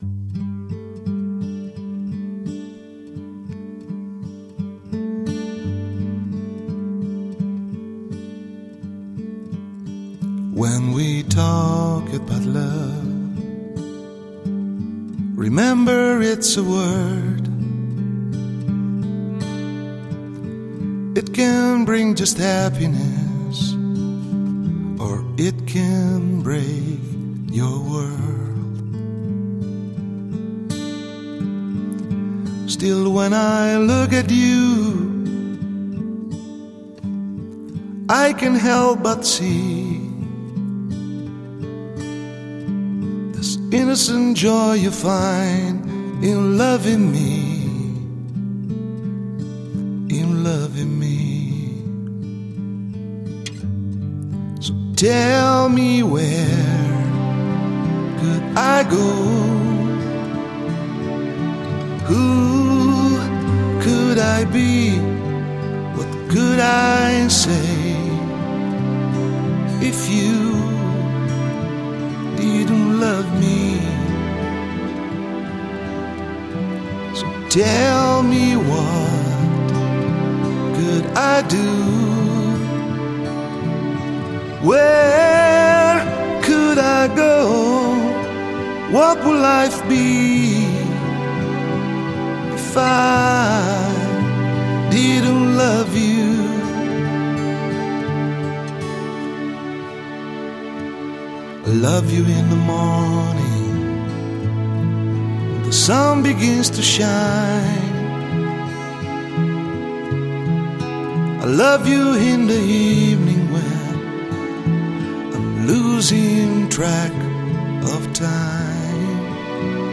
Vocals - Guitar
Keyboards